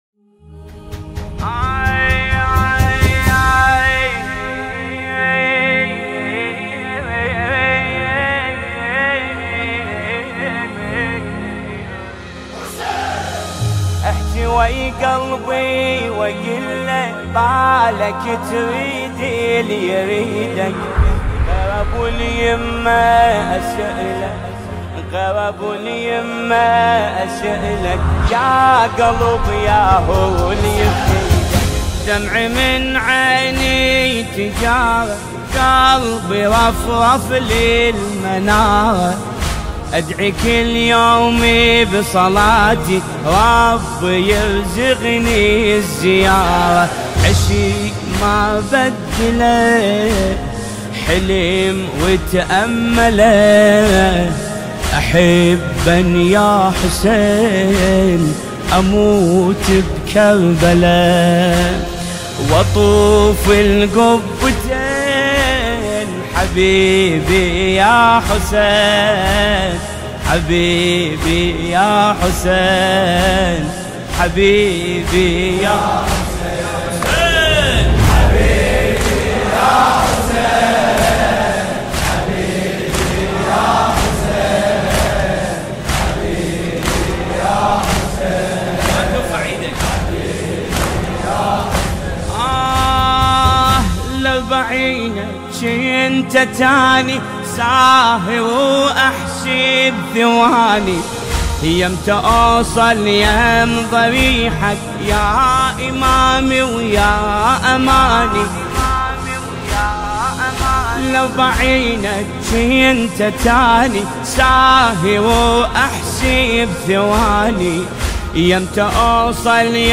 نماهنگ دلنشین عربی